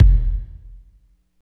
29.04 KICK.wav